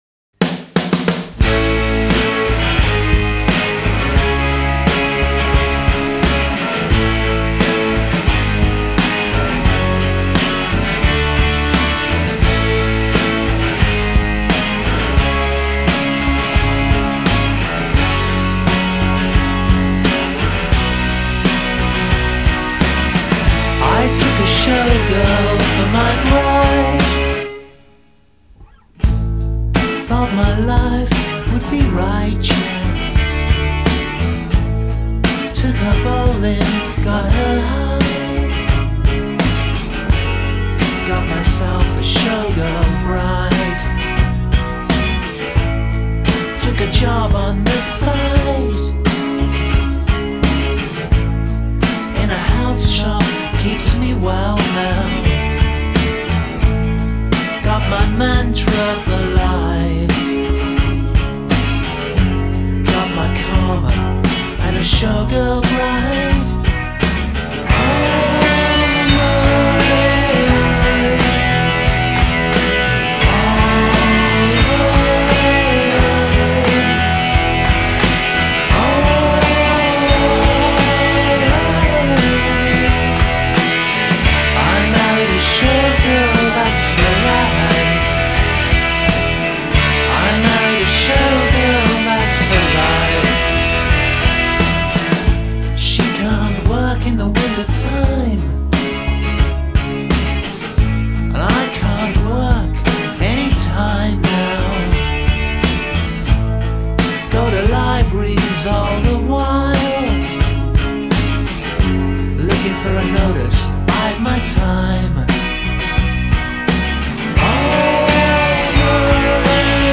Жанр: alternative rock, indie pop